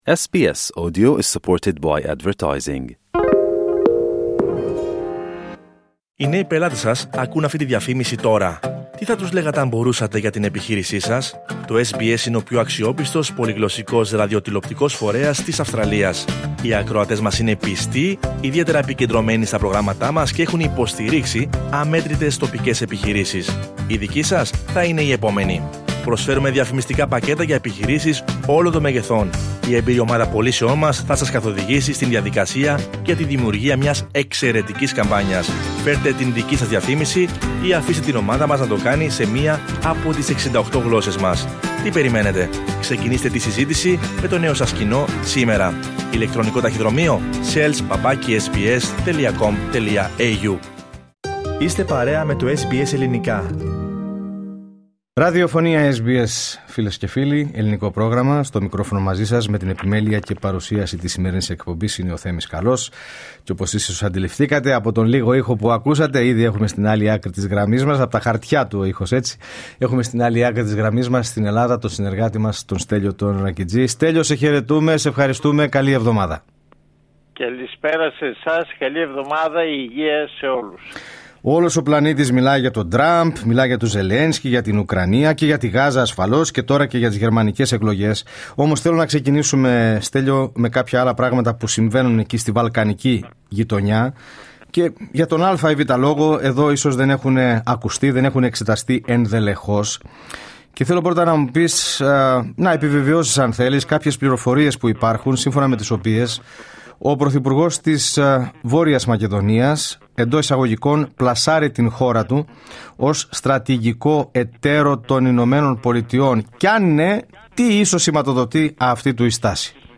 Ακούστε την εβδομαδιαία ανταπόκριση από την Ελλάδα